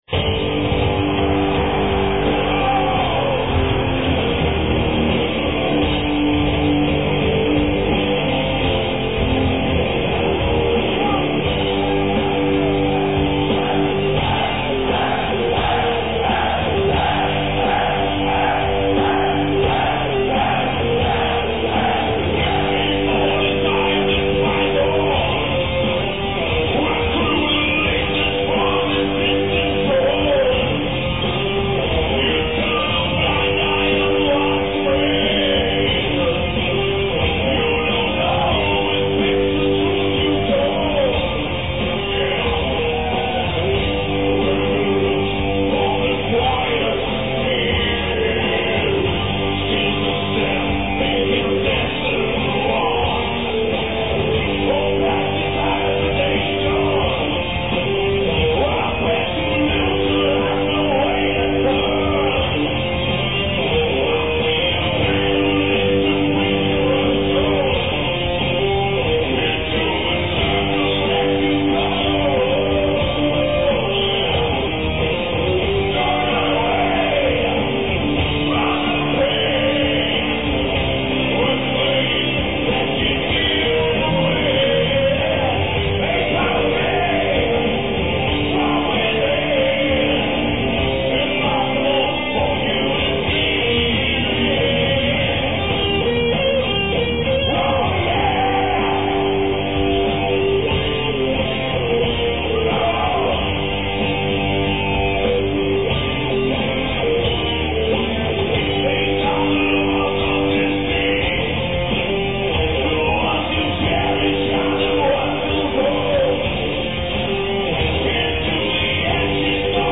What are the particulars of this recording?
European Tour 1993